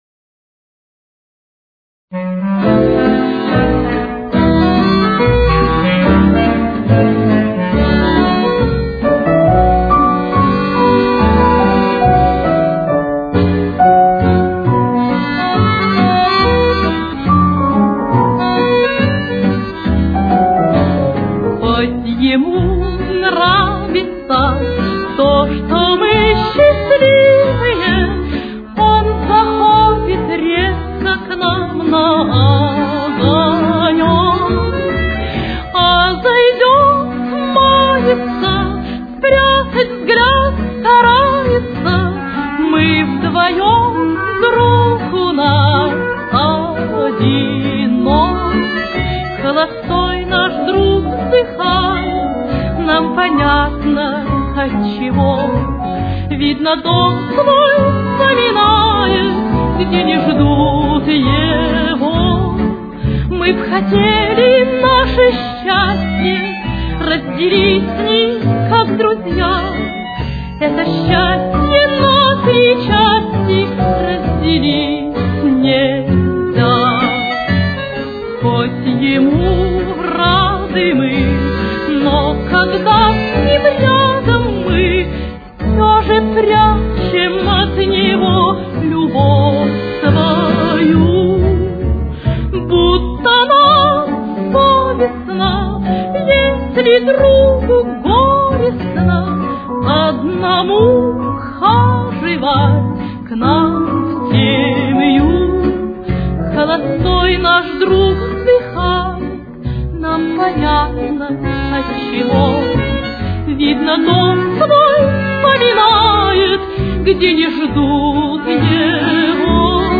Си минор. Темп: 70.